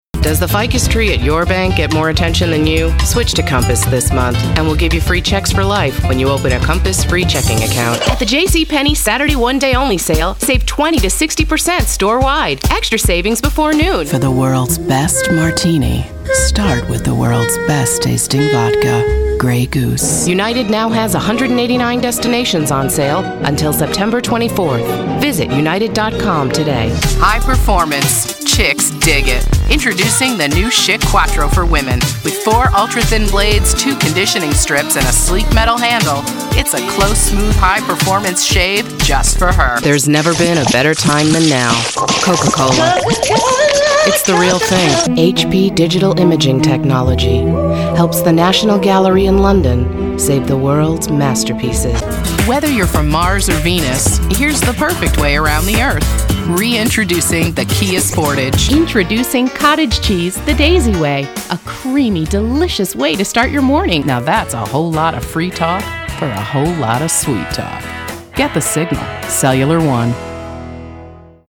English-speaking, mid vocal range, commercials, corporate, imaging, animation, home studio, professional, warm, intelligent, authentic, friendly
Sprechprobe: Werbung (Muttersprache):
VOICE TRAITS: wry, warm, sexy, authoritative, friendly, fun, real, mom, genuine, conversational, matter-of-fact, high energy, professional